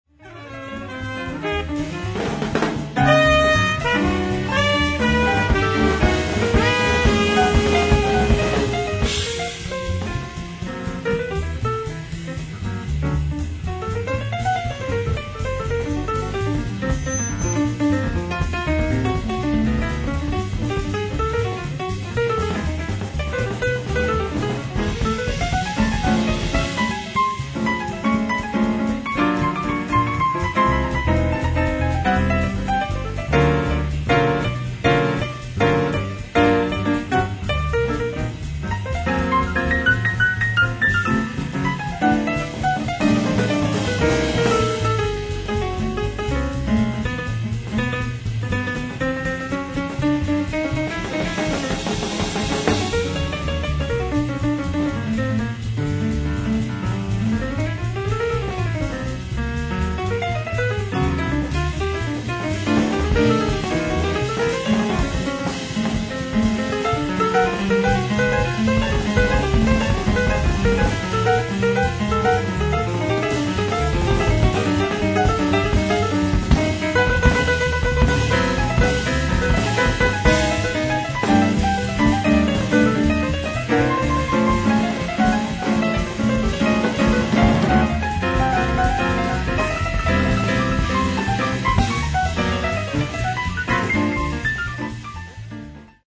ライブ・アット・ウェストベリー・ミュージックフェアー、ウェストベリー、ニューヨーク 06/19/1992
ツアー関係者から流出したサウンドボード音源！！
※試聴用に実際より音質を落としています。